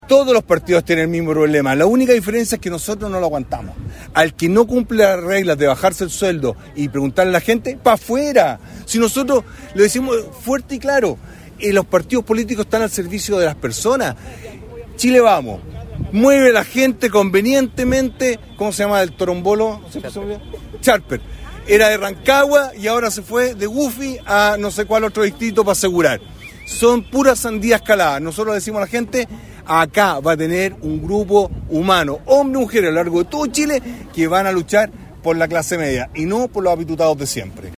Desde la Plaza Independencia, en el centro de Concepción, y rodeado por alrededor de un centenar de adherentes, el postulante del Partido de la Gente (PDG) presentó algunas de sus propuestas, entre las que figura la baja de sueldos en la administración del estado, la devolución del IVA en los medicamentos y el término de las devoluciones de gasto electoral.